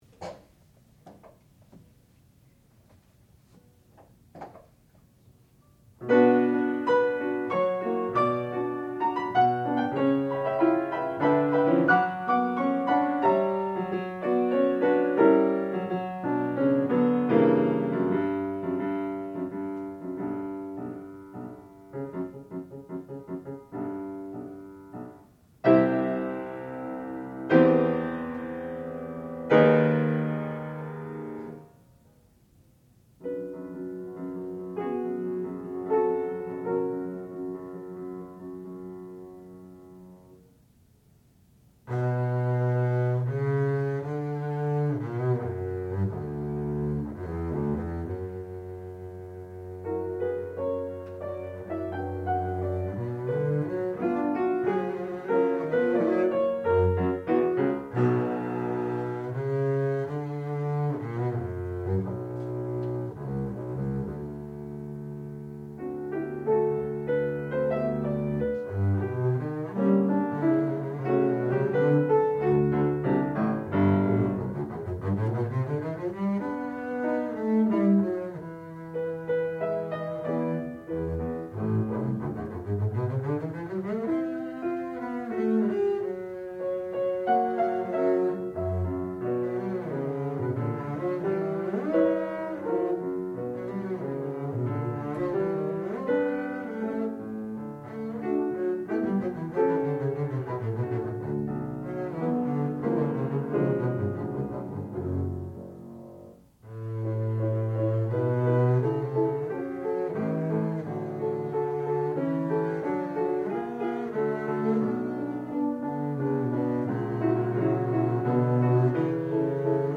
sound recording-musical
classical music
Qualifying Recital
double bass